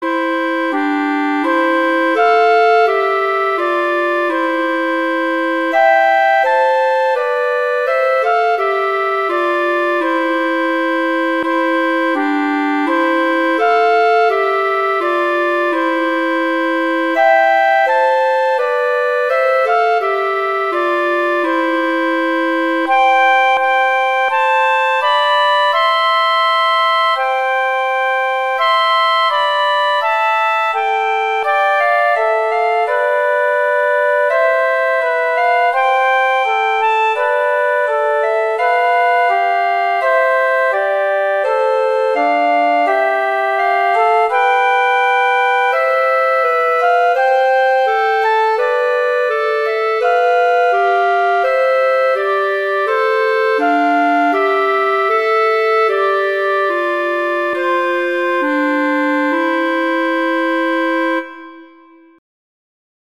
Instrumentation: flute & clarinet
arrangements for flute and clarinet